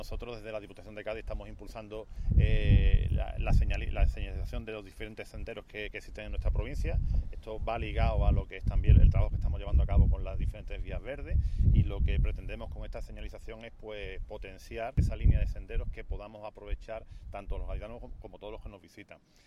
El vicepresidente segundo de la Diputación de Cádiz y responsable del Área de Transición Ecológica y Desarrollo a la Ciudadanía, Javier Vidal, ha presentado en Conil de la Frontera el programa Senderos de Cádiz. Esta iniciativa pretende establecer una red de senderos de gran recorrido en la provincia mediante la conexión de viales públicos de distinta naturaleza y titularidad.
JAVIER-VIDAL-SENDEROS-DE-CADIZ-MP3.mp3